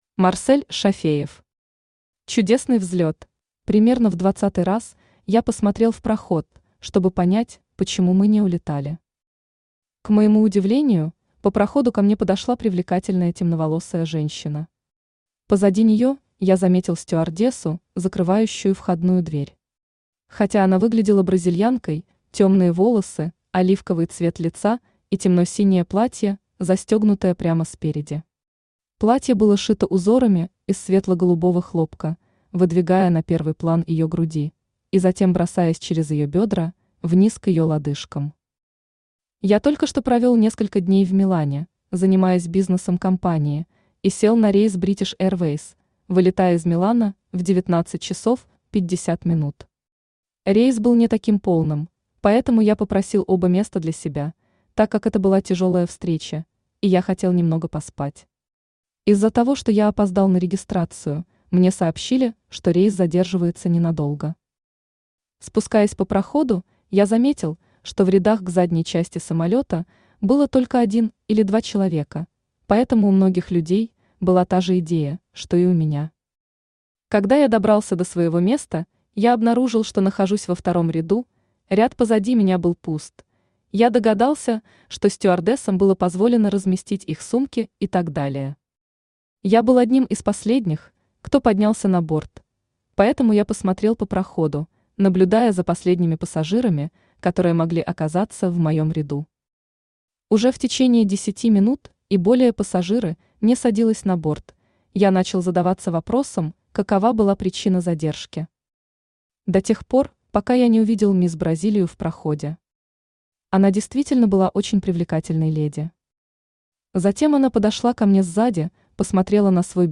Aудиокнига Чудесный взлет Автор Марсель Зуфарович Шафеев Читает аудиокнигу Авточтец ЛитРес.